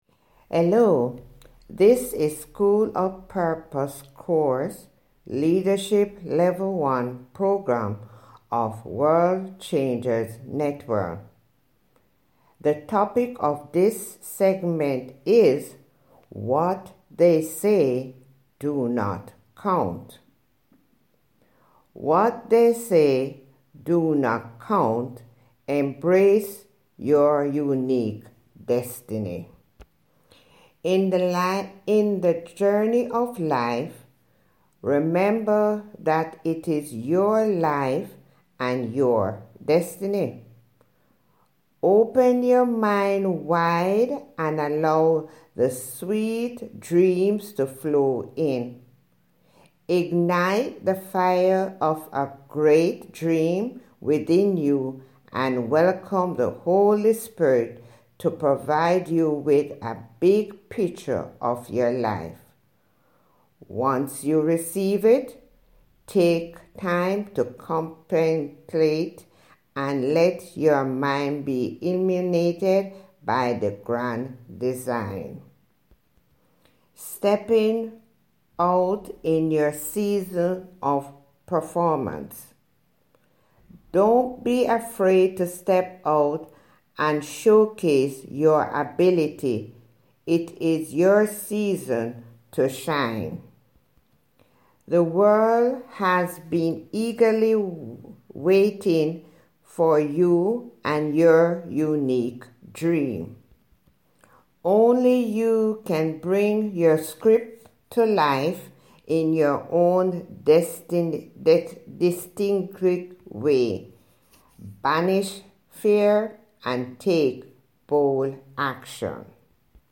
Listen to Human Voice Reading Here: